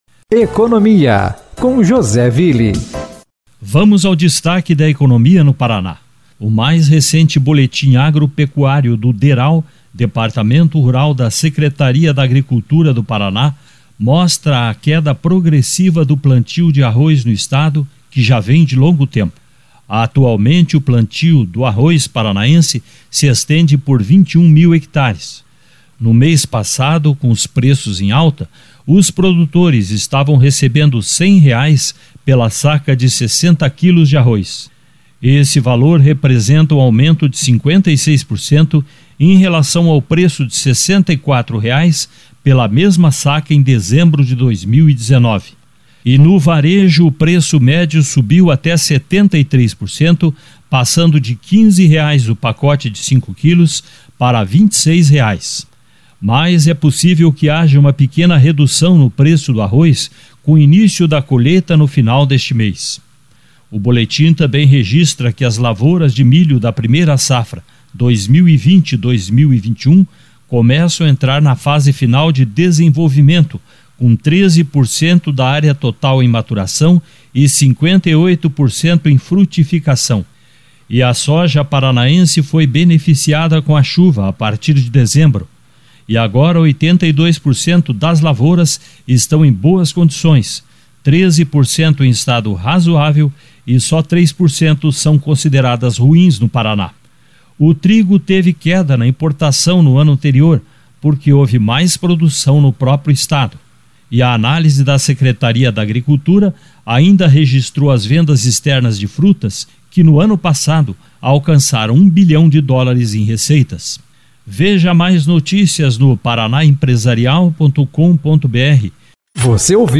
Deral aponta queda progressiva do plantio do arroz no Paraná. Mais informações da área econômica no boletim